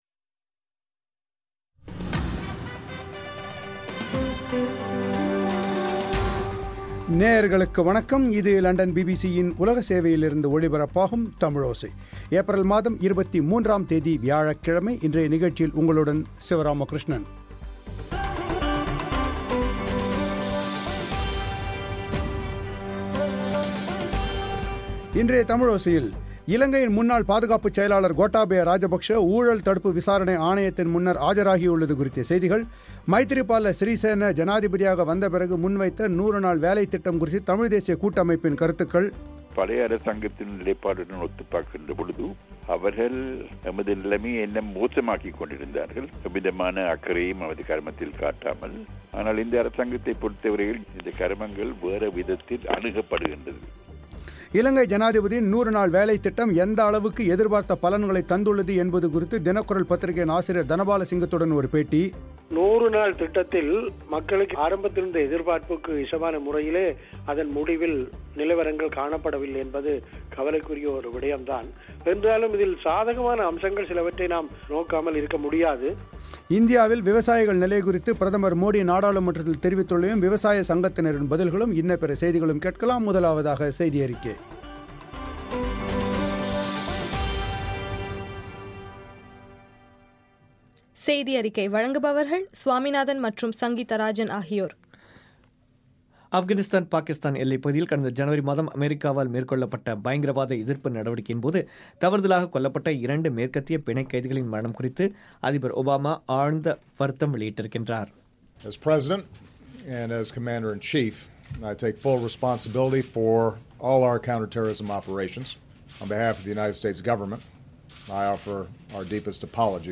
ஒரு பேட்டி